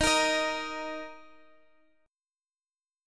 D#5 chord